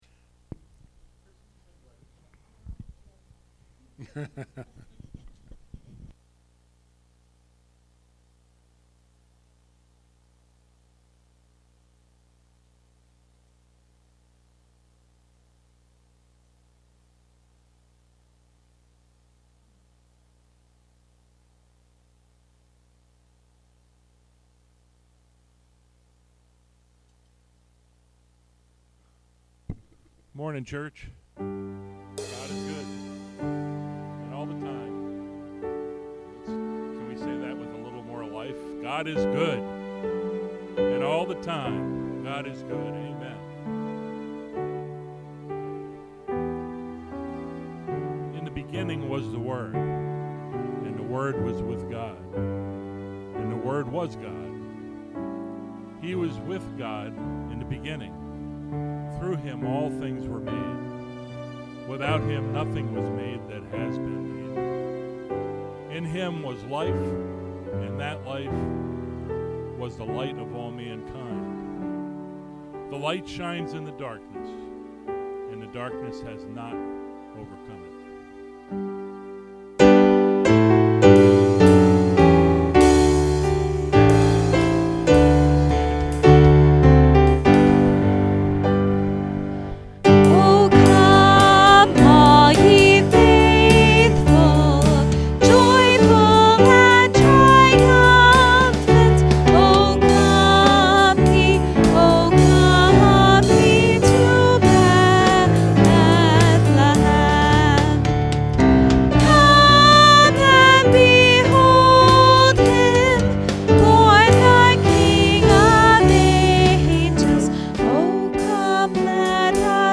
Podcast (sermons)